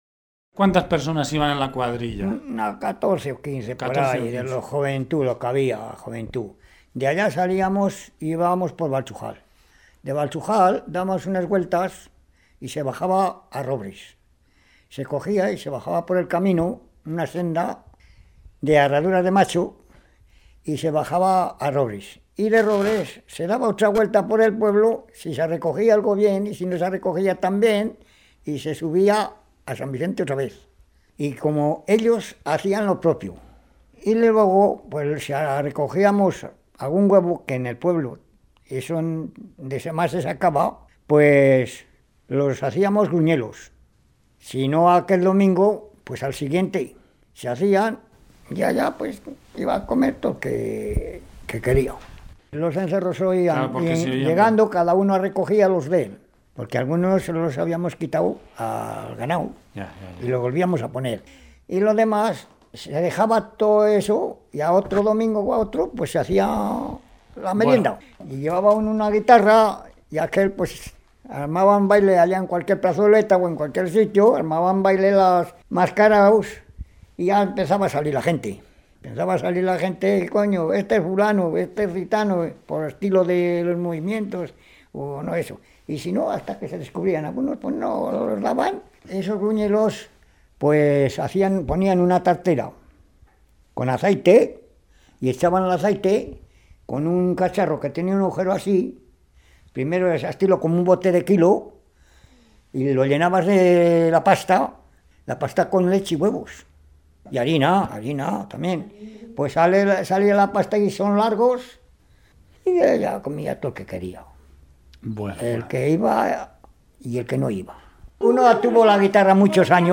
Clasificación: Cancionero
Lugar y fecha de recogida: Logroño, 19 de enero de 2001